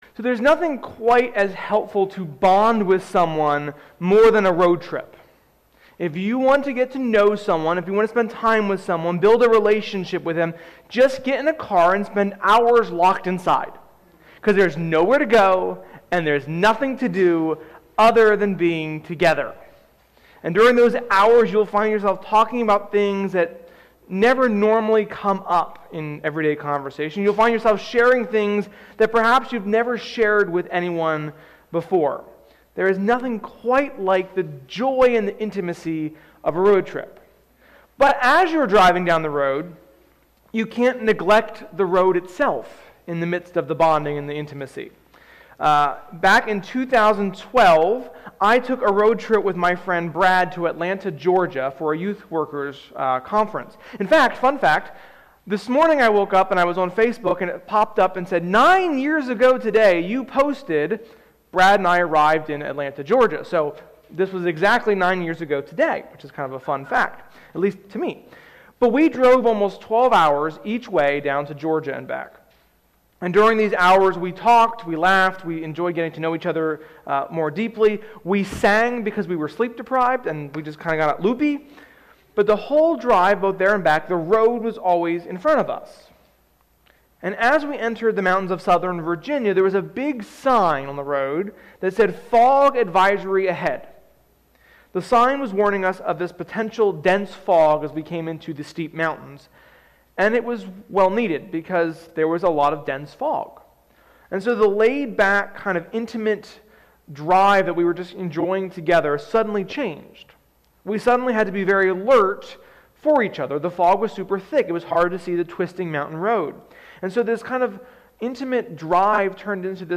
Sermon-4.25.21.mp3